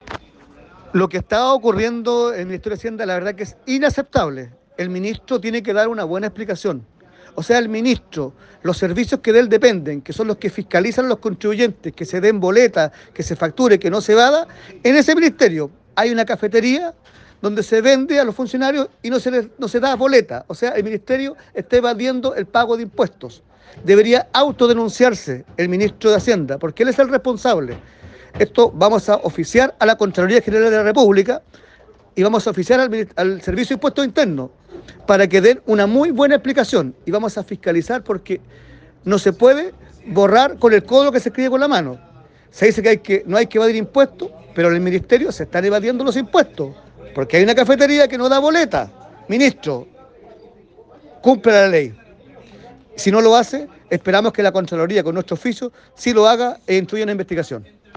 henry-leal-diputado-udi.mp3